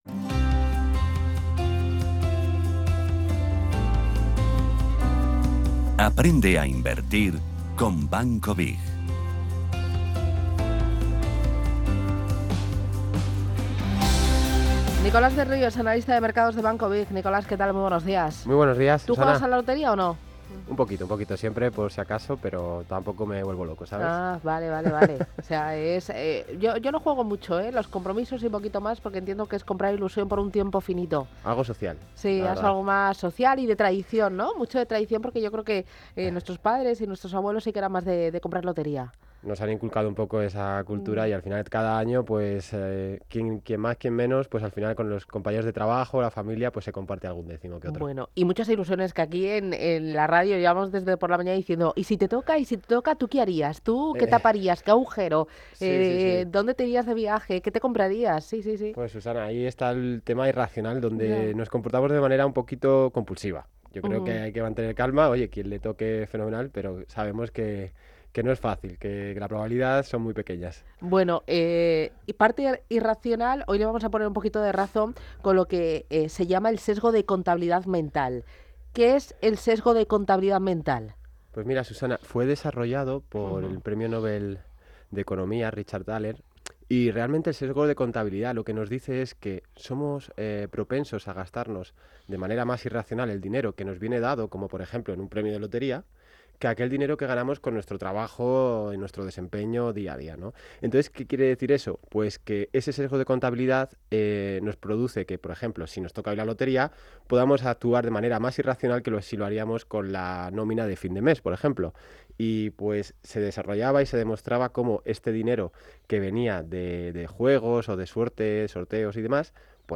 Sección "Aprende a Invertir con BiG" junto a Radio Intereconomía